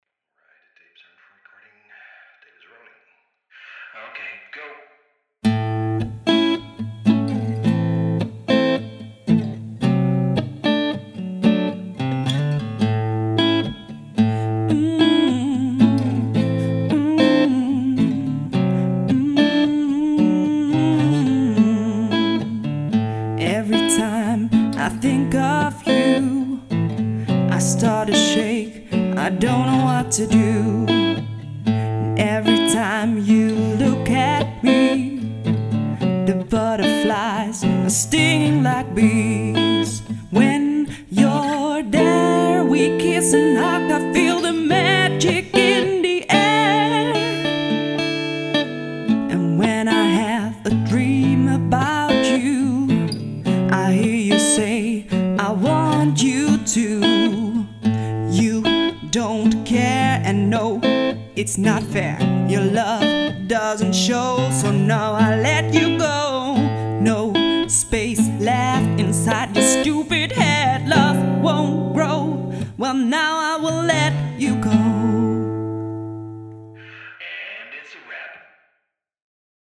de ruige en toch tedere stem